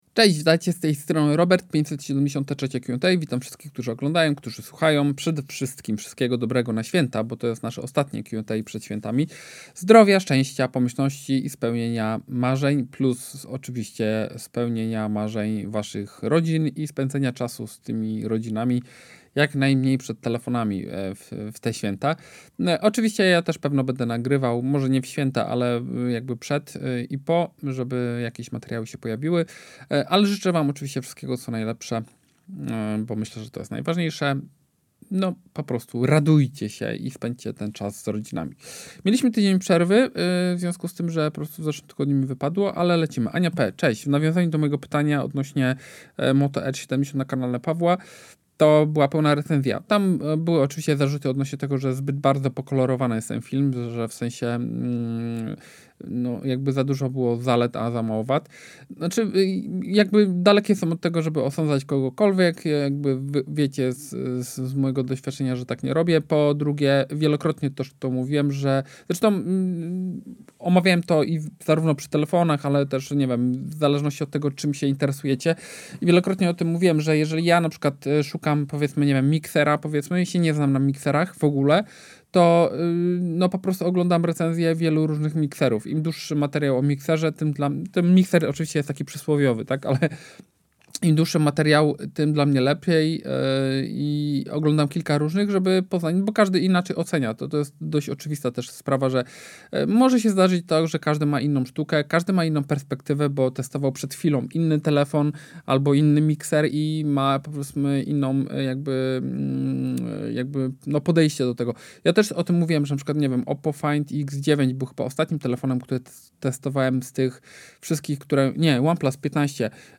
Audycja Q&A z kanału YouTube